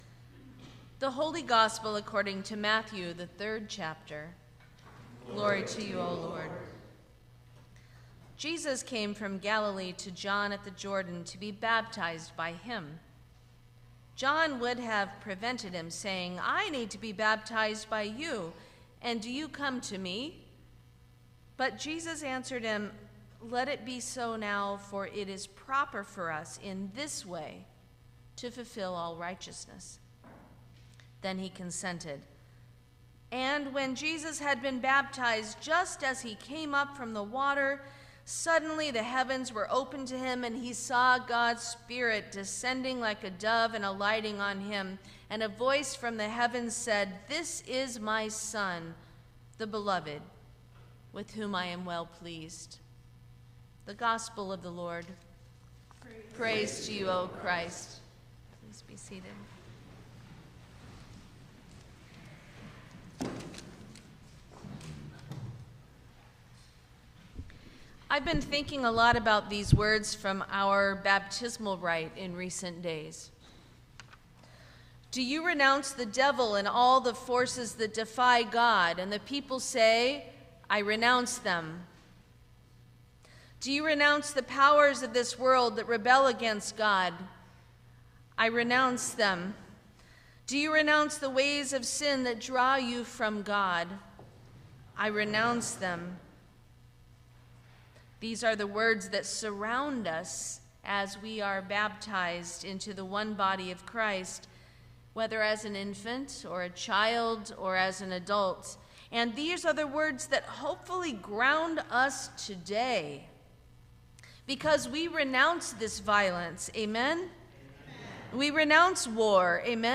Sermon for the Baptism of Our Lord 2026